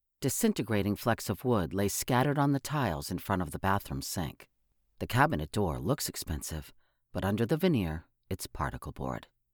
De-clicker settings - muffled sound?
However, I think the settings are wrong–the finished product comes out muffled
They are mostly mouth noises, which I did my best to control. Some are random clicks–assuming background noise and moving chair or whatnot.
BTW I think you’re a bit too close to the mic: it’s rattling in places from the loudness of your voice.
The inter-word noise in that test clip comes in somewhere in the -70s (normally good) but ACX Check reports -60.3dB. The limit is -60dB.